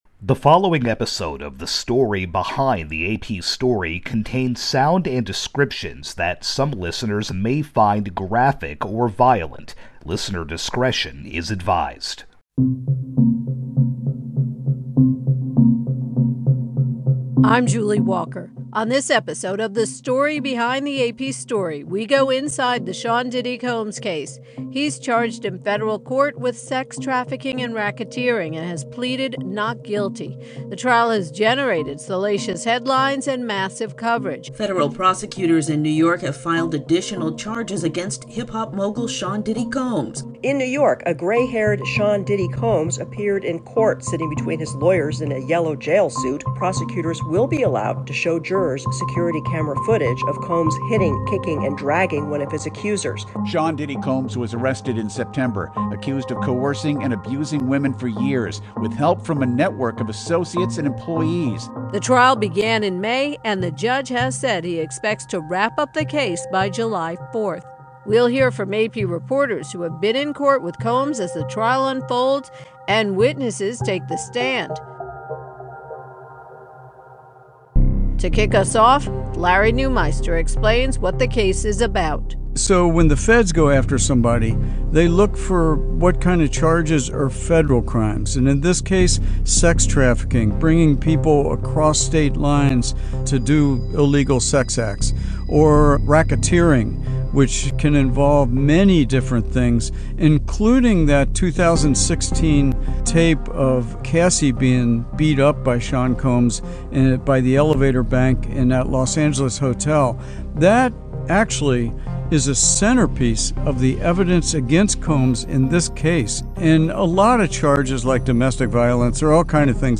speaks with reporters